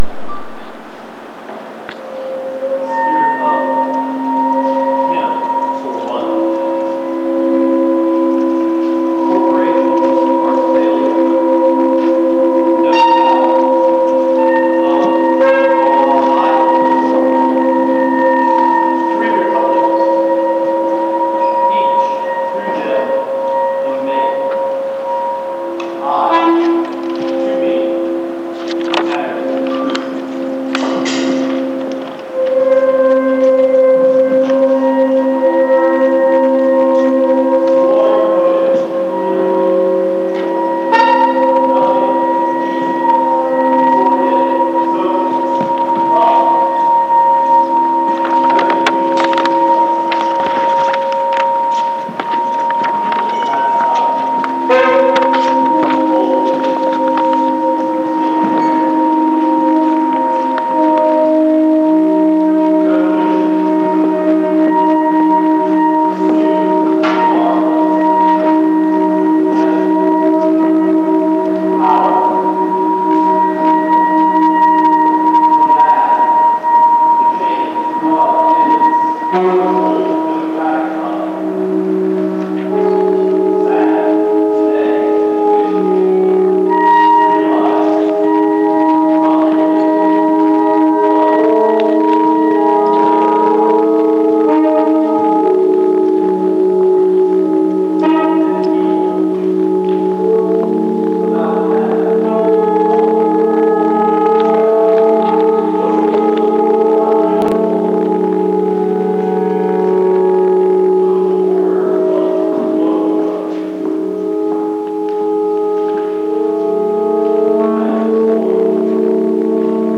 The culmination of Ten Thousand Things was a Musicircus held at the Goat Farm and included the Atlanta Poets Group, Bent Frequency and the Chamber Cartel .
Here is a recording of the event from the Empty Words location (indicated by a box in the map below).
At minute the entire room performs 4'33" (and you can hear the freight train passing by fairly clearly).